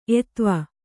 ♪ etva